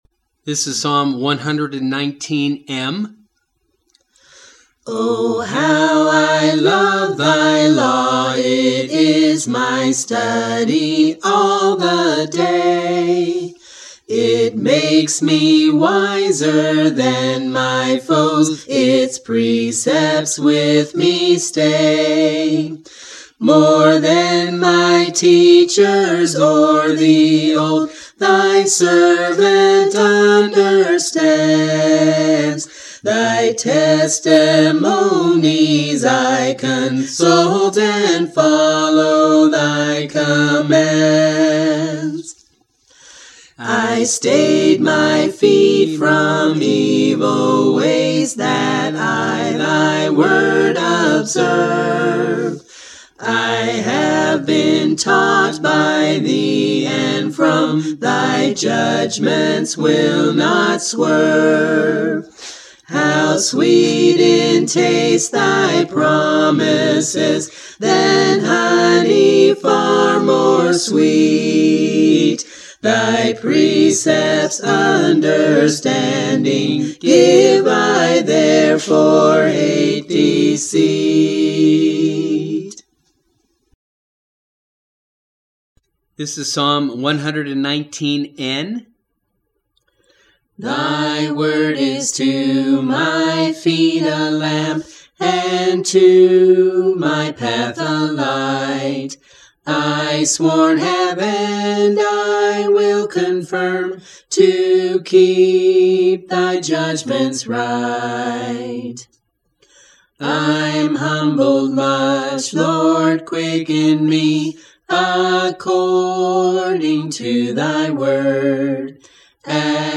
Psalm Singing – May 2024